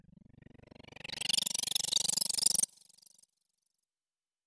Fly By.wav